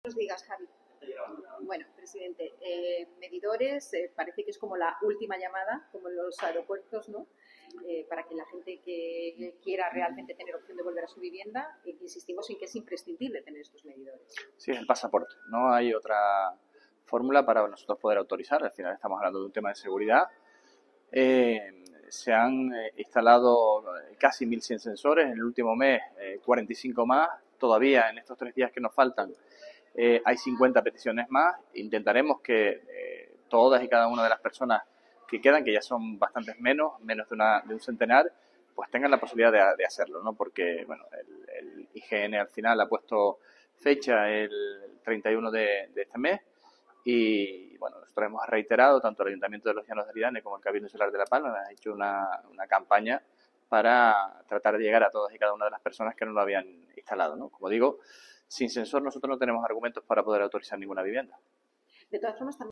Declaraciones Sergio Rodríguez Peinpal.mp3